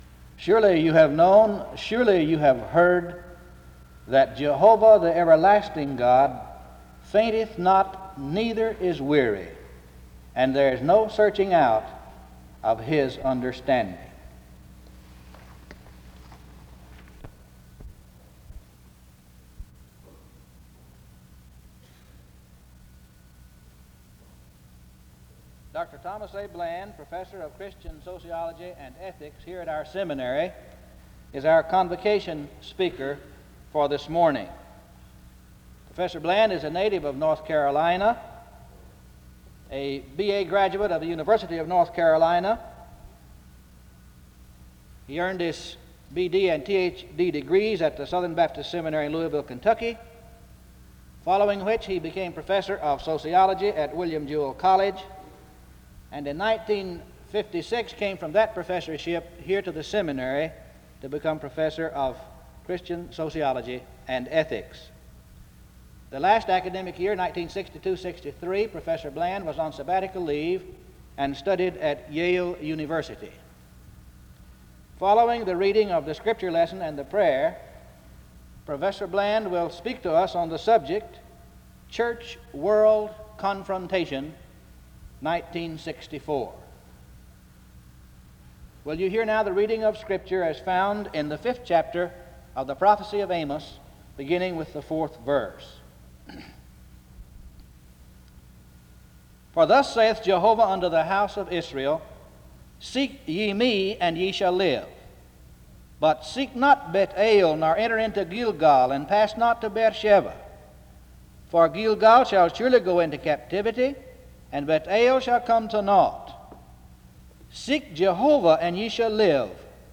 The service begins with an introduction to the speaker and the reading of Amos 5:4-27 from 0:00-5:13.
A prayer is given from 5:15-6:20.
A closing prayer is offered from 41:48-42:01.
SEBTS Chapel and Special Event Recordings SEBTS Chapel and Special Event Recordings